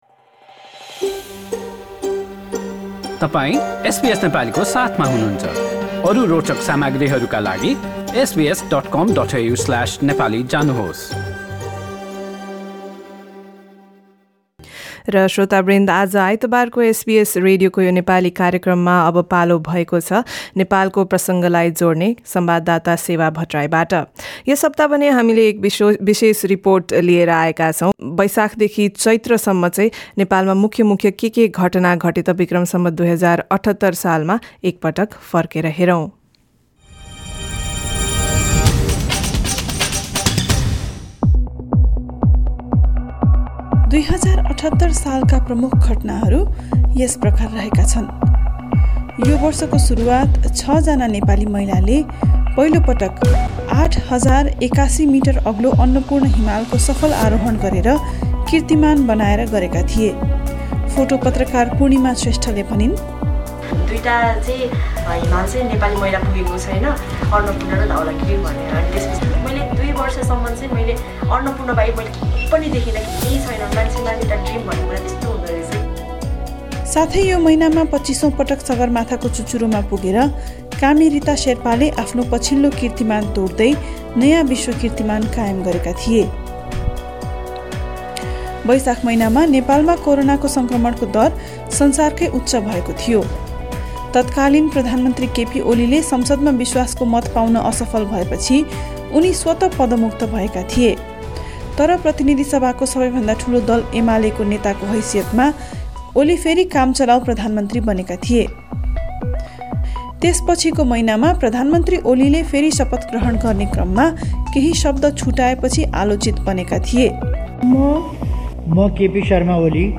A compilation of some news stories from Nepal during 2078 B.S. Source: Compiled from various sources